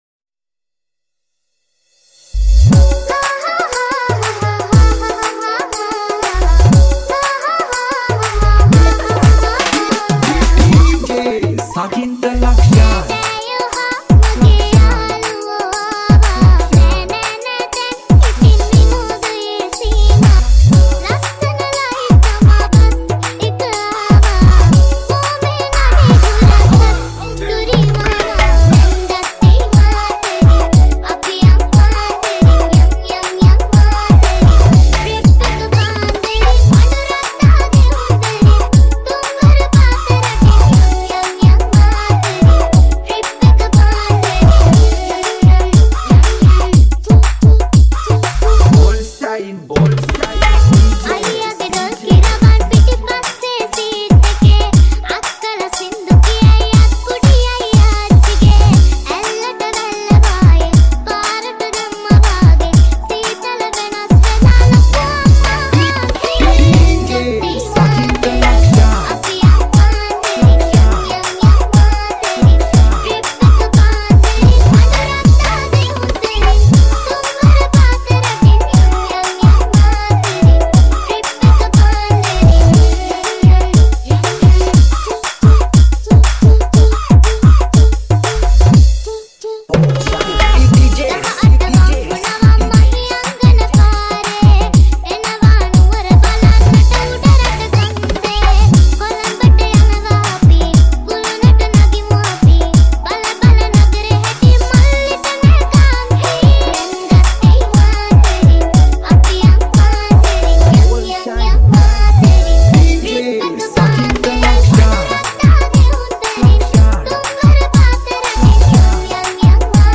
High quality Sri Lankan remix MP3 (2.6).